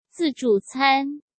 zì zhù cān